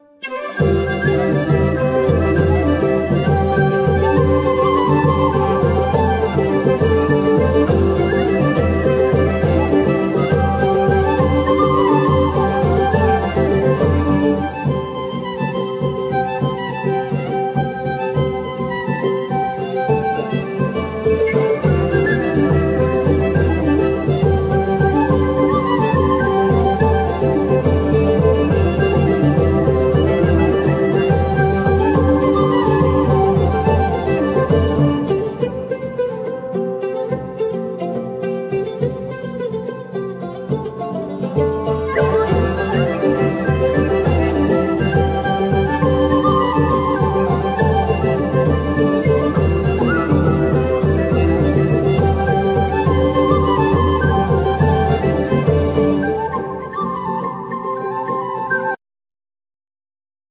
Flute,Percussions,Gittern,Vocals,Shawm,Harp
Gittern,Saz,Tarabuka,Vocals,Hurdy gurdy
Shawm,Bagpipes,Harp
Santur,Fiddle,Davul,Percussions